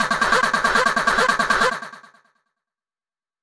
Techno / Voice / VOICEFX258_TEKNO_140_X_SC2(L).wav